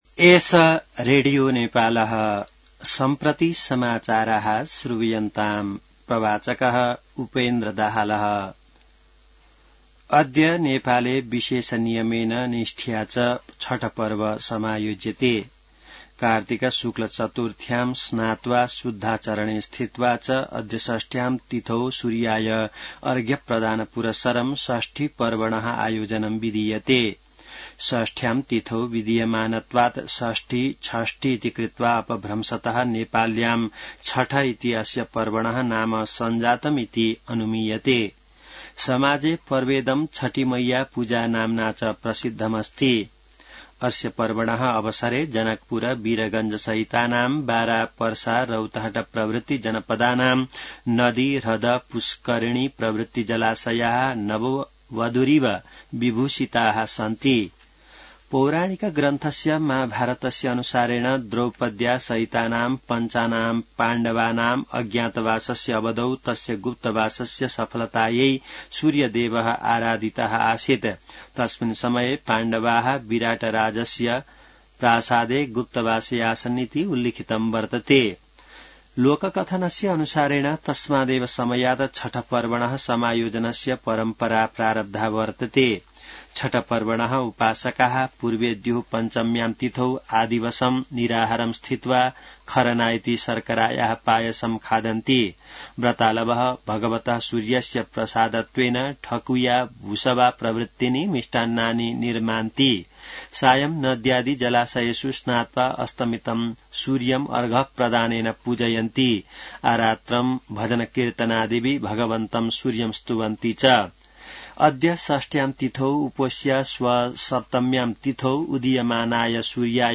संस्कृत समाचार : १० कार्तिक , २०८२